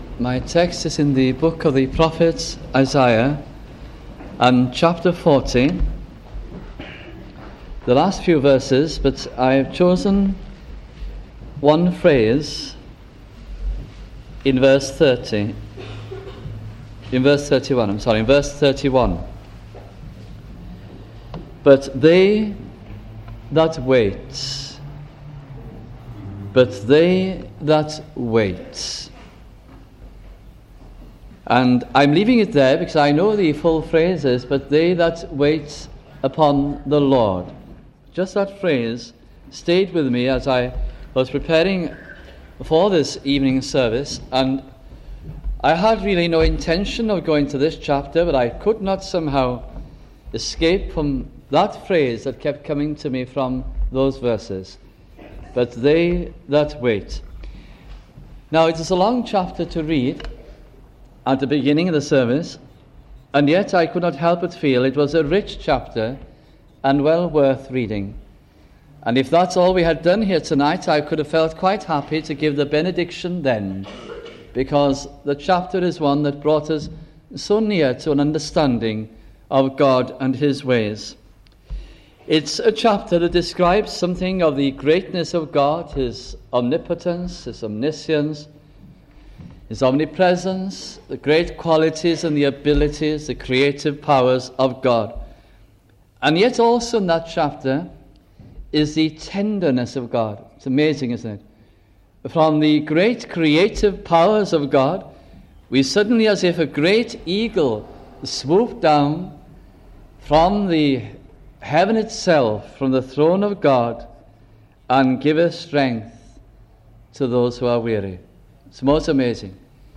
» Isaiah Gospel Sermons